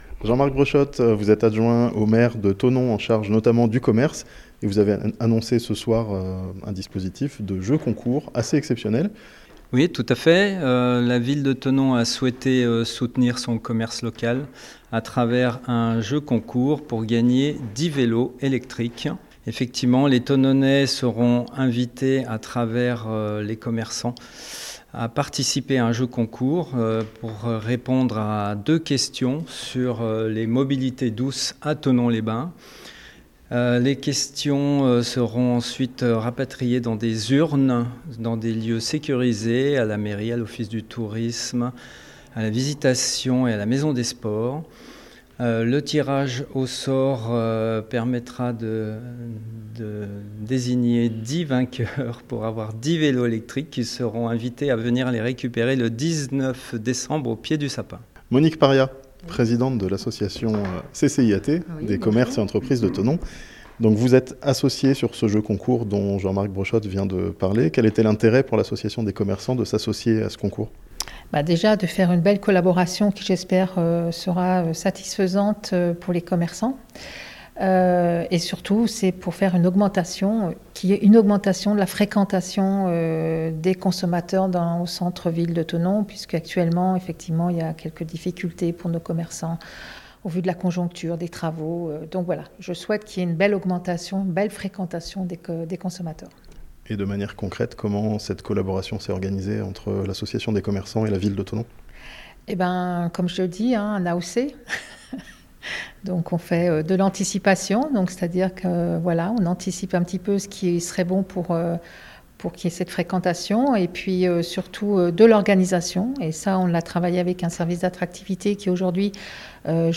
La ville de Thonon et son association de commerçants annoncent deux jeux concours pour relancer la fréquentation du centre-ville (interview)